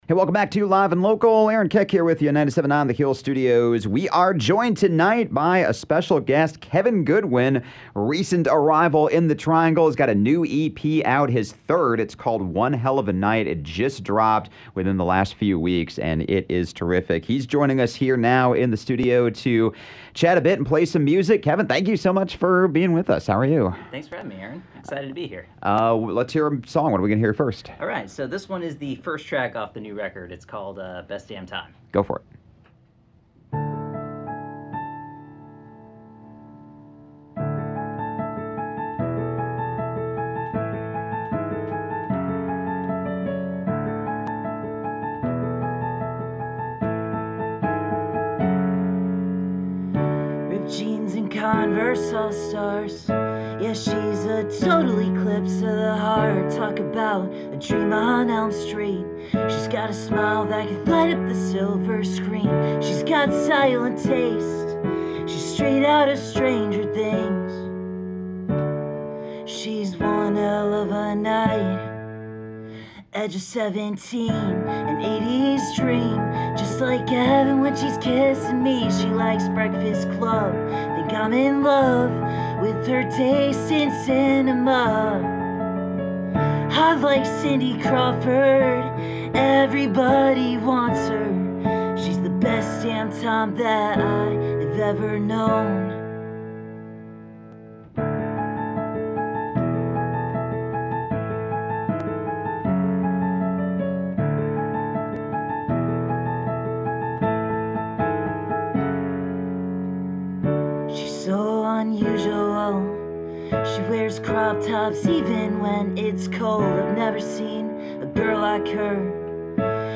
is a singer-songwriter with a broad range
” an instrumental he originally wrote as a kid.